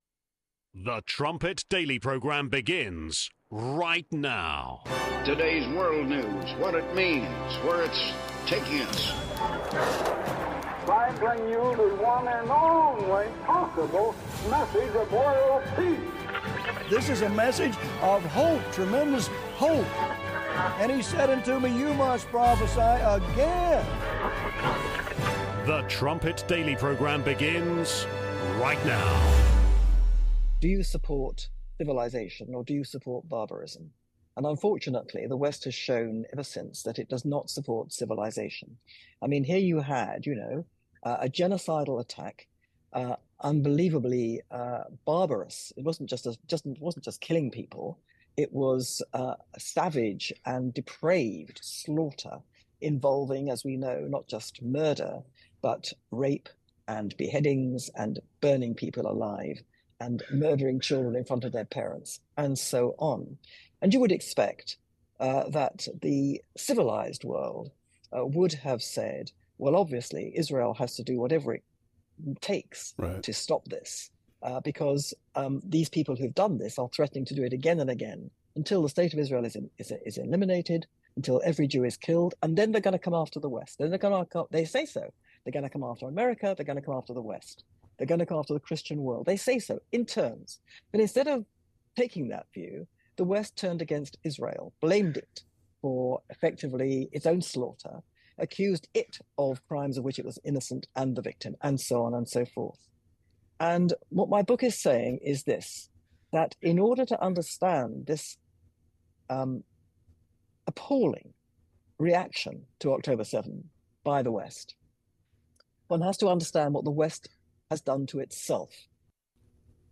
28:45 Trumpet Daily Exclusive Interview: Melanie Phillips (27 minutes)